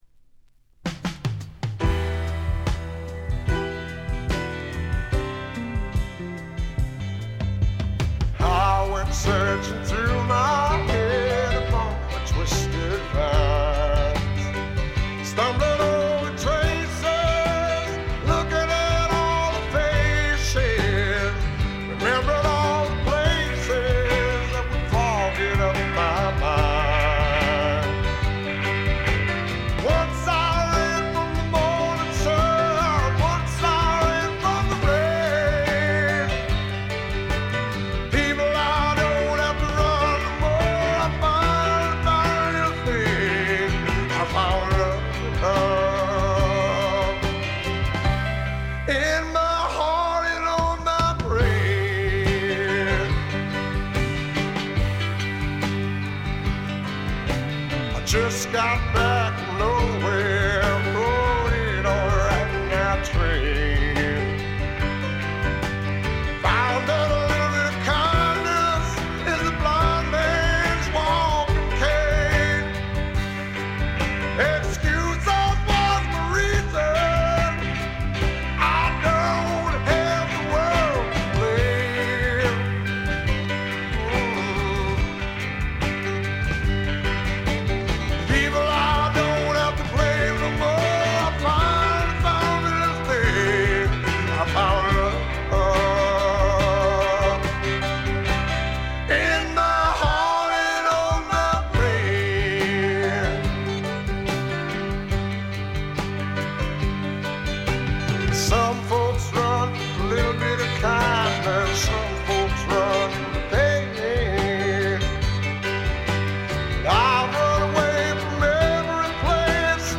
ごくわずかなノイズ感のみ。
サイケ＆スワンプの傑作！
試聴曲は現品からの取り込み音源です。